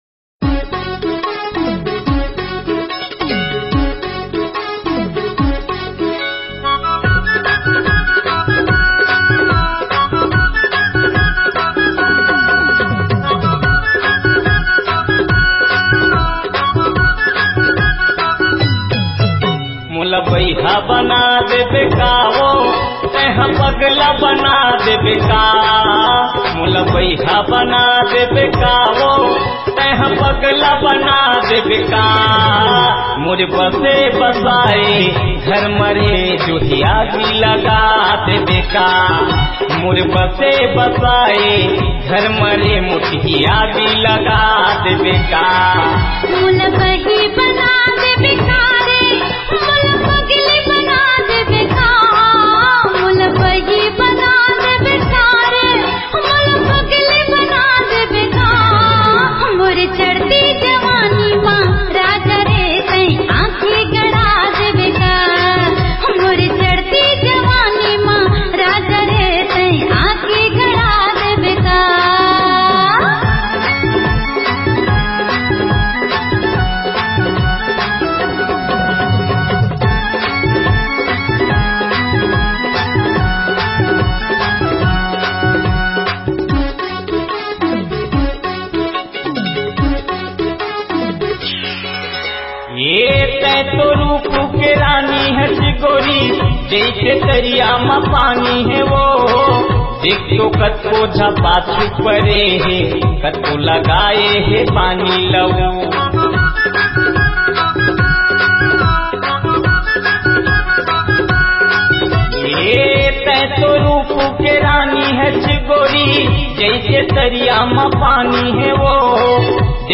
Cg Dj Song